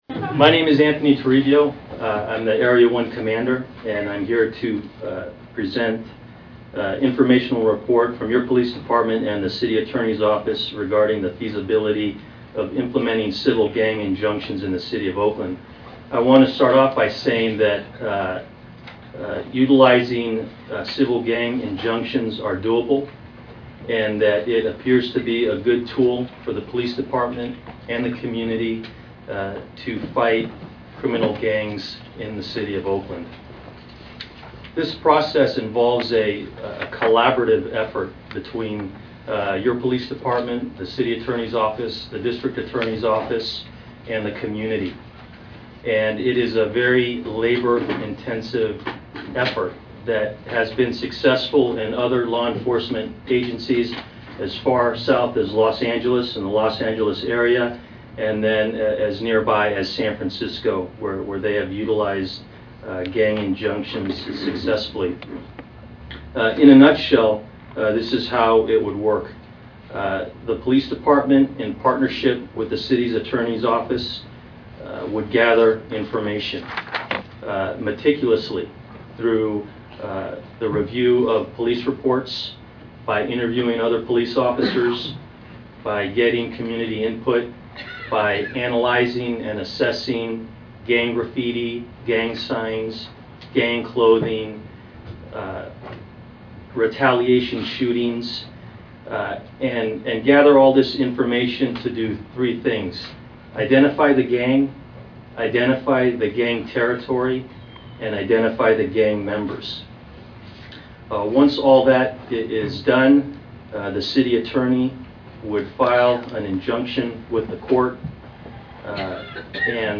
Oakland City Councilperson Larry Reid had requested that OPD and the City Attorney's office craft a proposal to implement gang injunctions in Oakland. At this City Council Public Safety Committee meeting, the proposal was presented to committee members Patricia Kernighan, Jean Quan, Larry Reid, and Nancy Nadel. Numerous community members spoke against bringing gang injunctions to Oakland, one spoke in favor. When it became apparent that committee members were cool to the idea, committee chairman Reid threw a little tantrum.